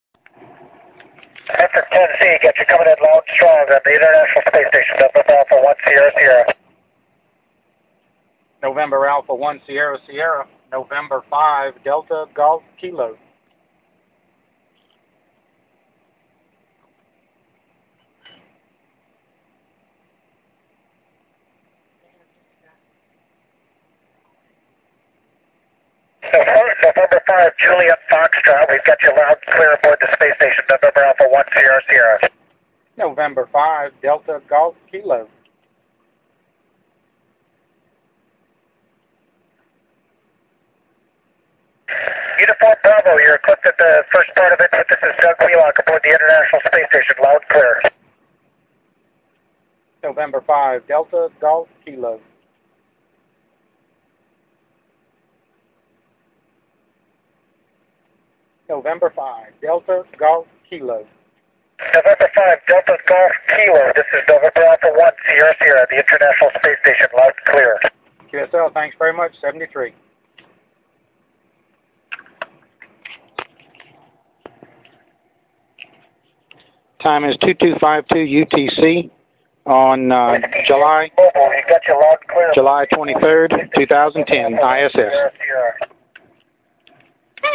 A recording of my contact with the ISS.